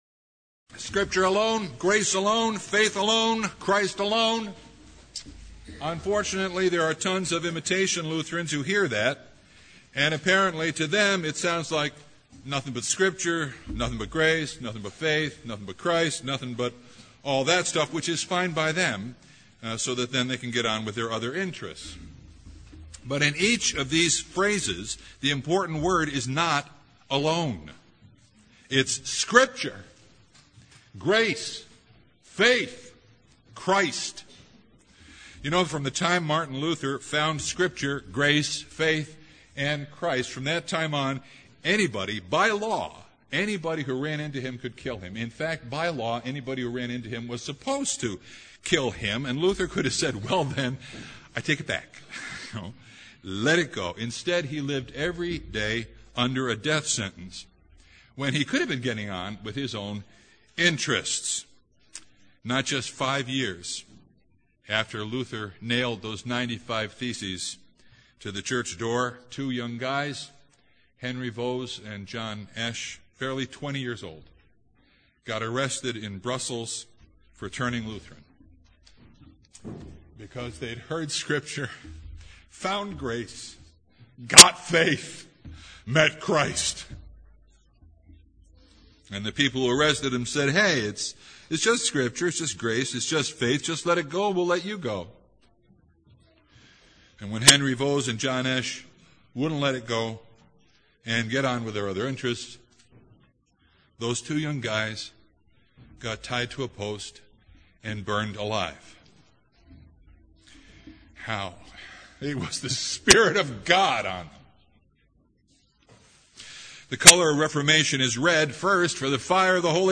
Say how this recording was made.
Passage: Ephesians 2:8 Service Type: Sunday